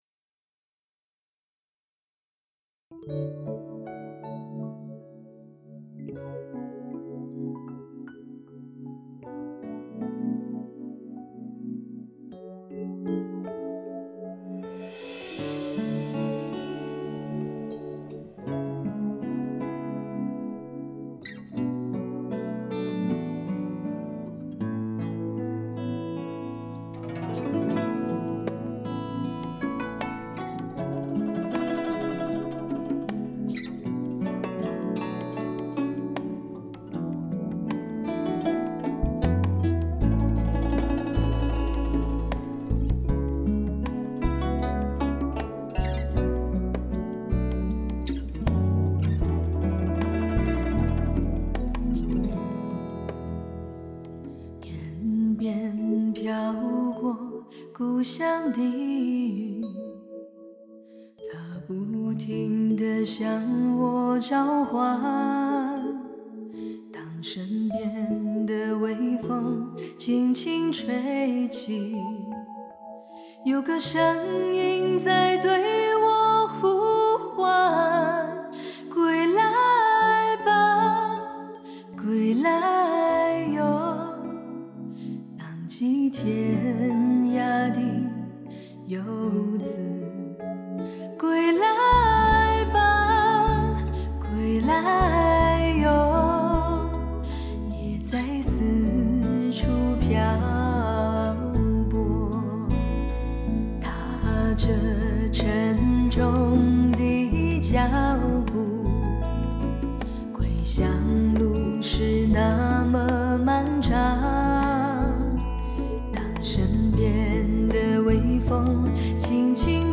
多首歌以纯吉他为主配器